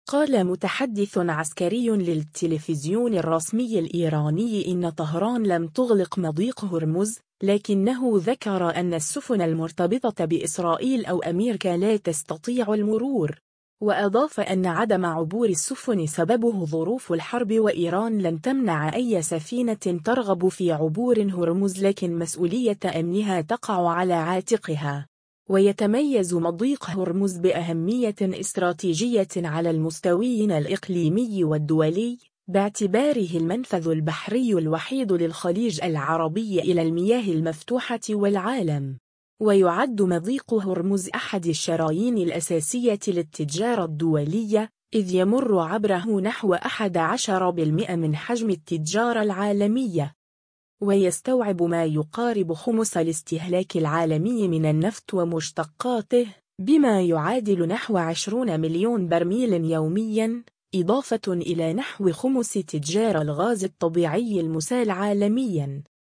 قال متحدث عسكري للتلفزيون الرسمي الإيراني إن طهران لم تغلق مضيق هرمز، لكنه ذكر أن السفن المرتبطة بإسرائيل أو أميركا لا تستطيع المرور.